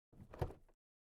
car_door_open_002.wav